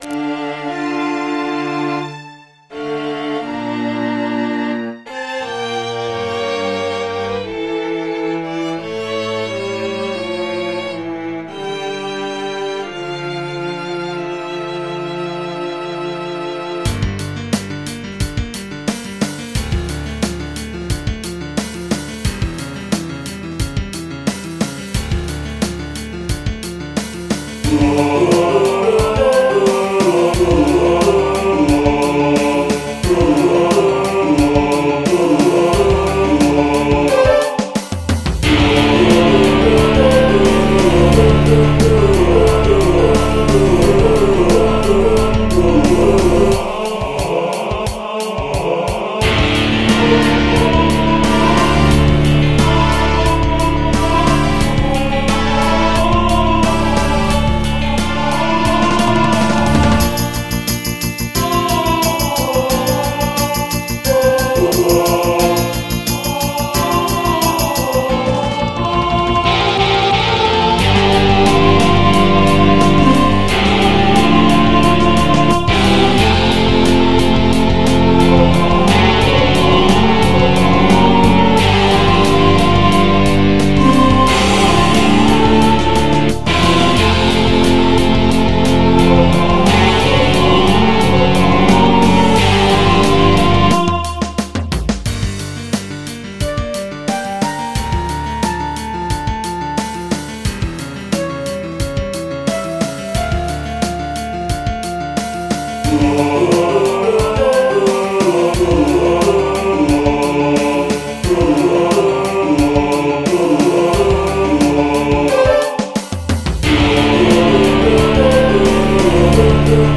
MP3 (Converted)